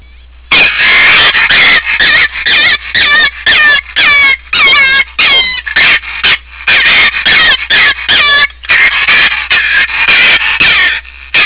Grey Fox Pup